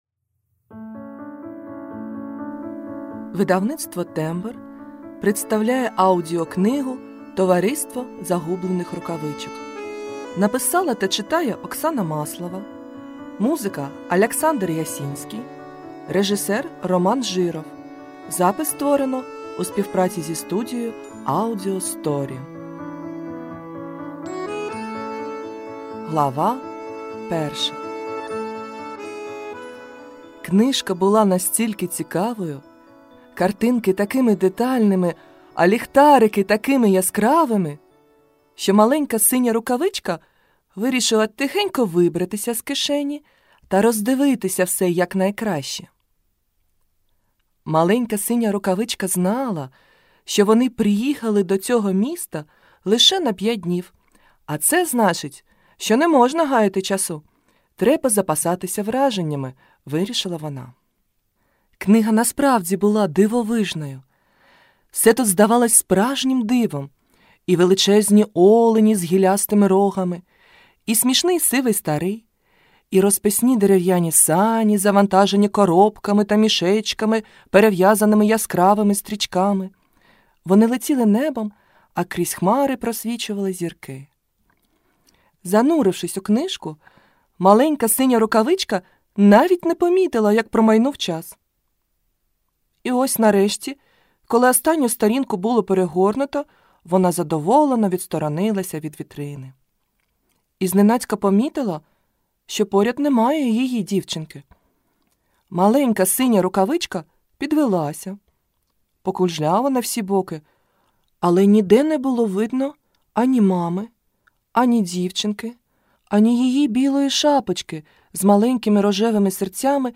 Ukázka z knihy
A když se zdá, že už se nic nedá změnit, stane se zázrak. Zimní pohádka se přece bez zázraku obejít nemůže!